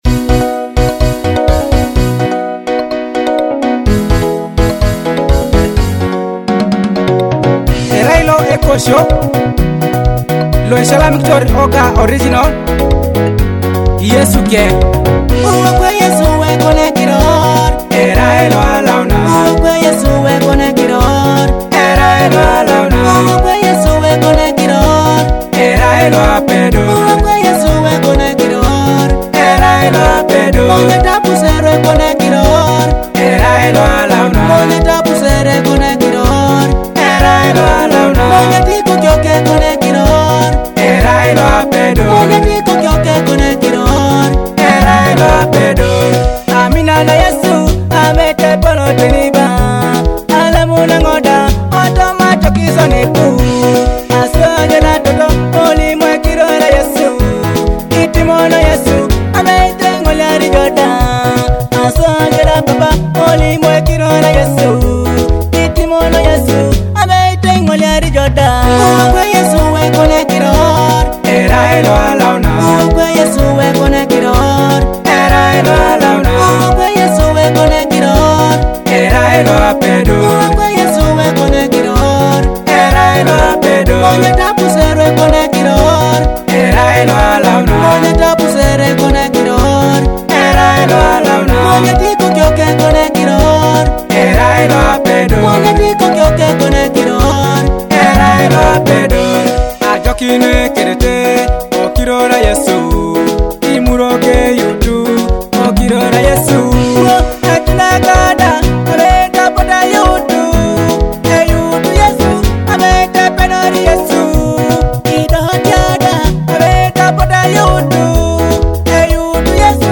a powerful gospel hit that celebrates the holy name of Jesus